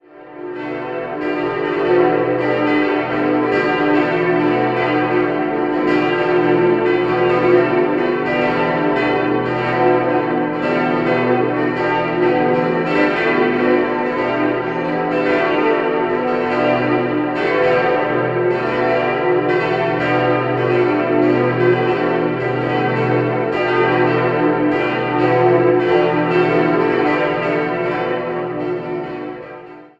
5-stimmiges Geläute: h°-d'-e'-fis'-a' Die zweitkleinste Glocke stammt noch aus dem ersten Geläut und wurde 1906 von Franz Schilling in Apolda gegossen.